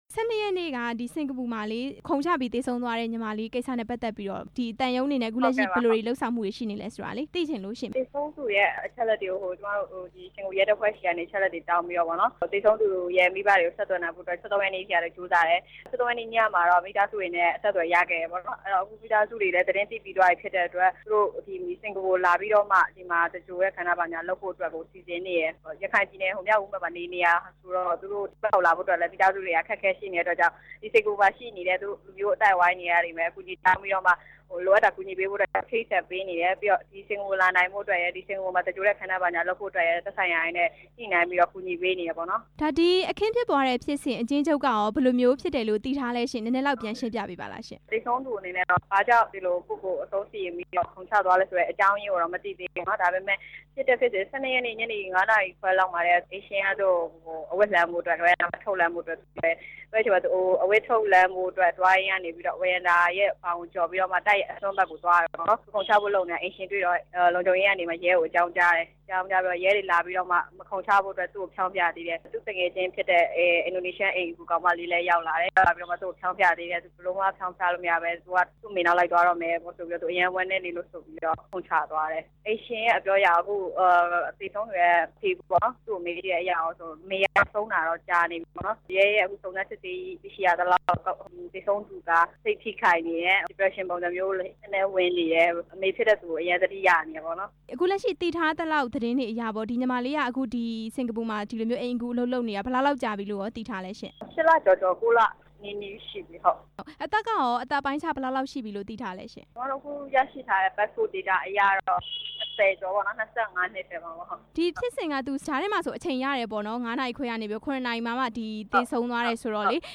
စကာၤပူမှာ ခုန်ချသေဆုံးခဲ့တဲ့ အိမ်ဖော် နောက်ဆက်တွဲအခြေအနေ မေးမြန်းချက်